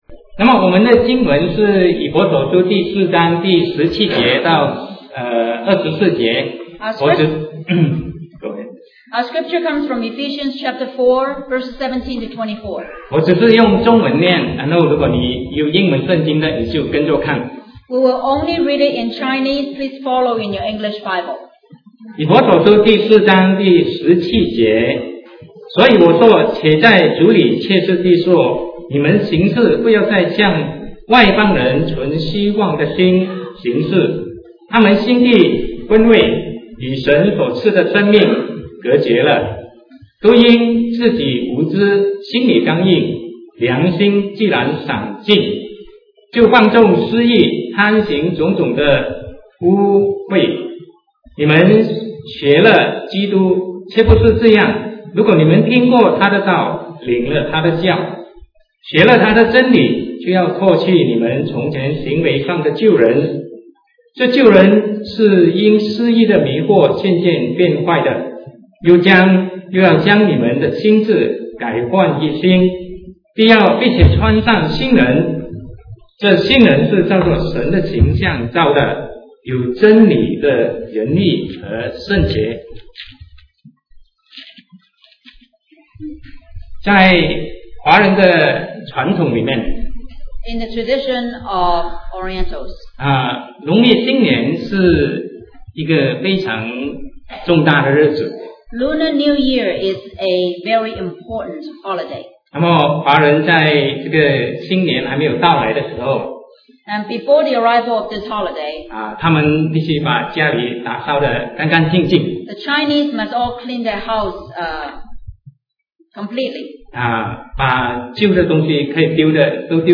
Sermon 2008-08-24 We are No Longer the Same as Before